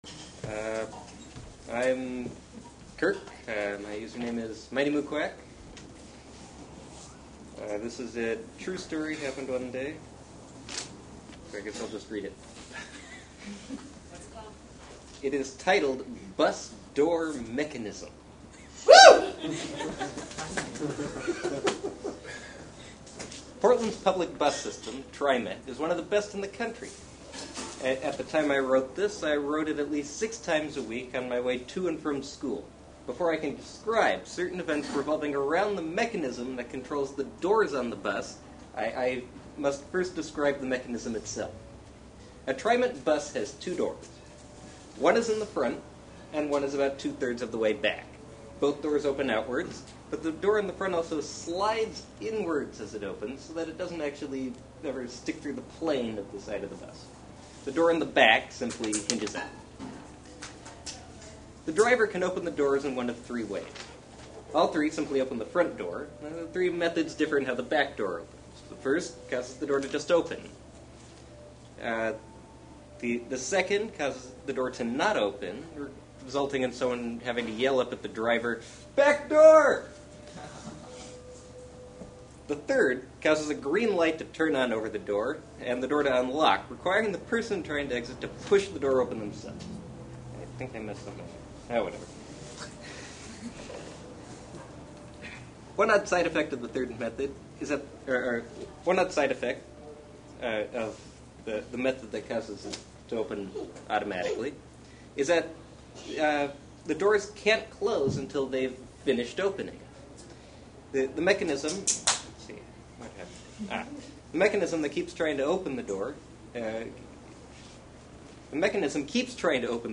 However, since they were actually physically present with each other, they decided to read to each other some of what they wrote out loud. This happened at an IWW union hall in southeast portland on Saturday night.